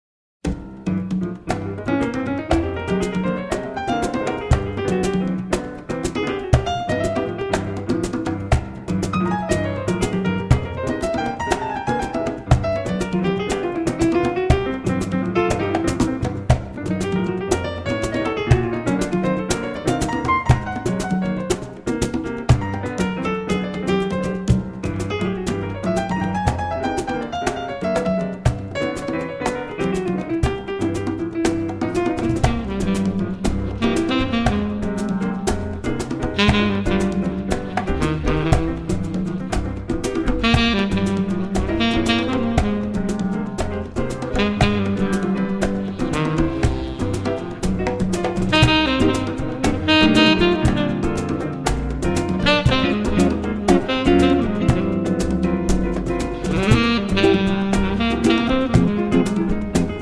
A great balance of latin jazz styles.
tenor saxophone, flute, piccolo
piano, accordion